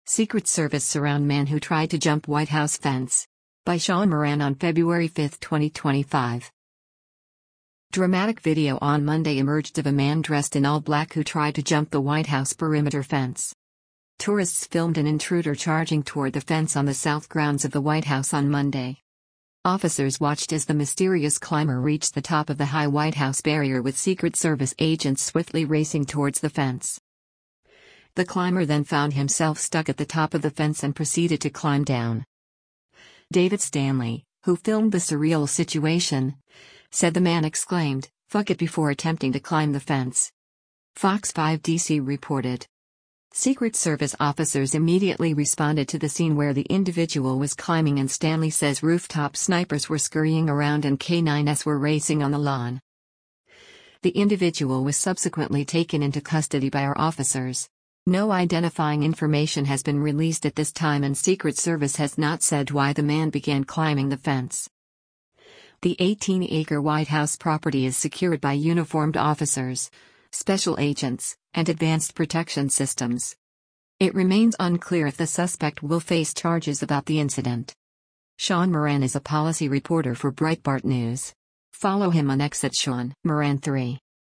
Tourists filmed an intruder charging toward the fence on the South Grounds of the White House on Monday.